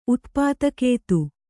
♪ utpātakētu